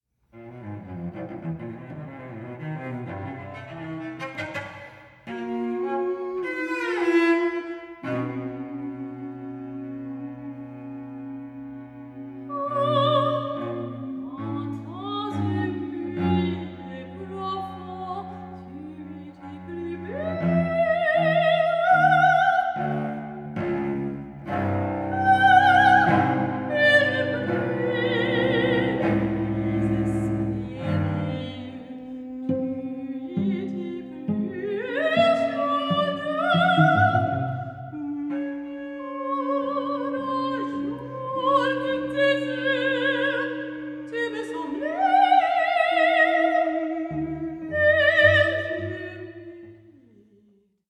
for high voice and singing cellist
soprano
cello/voice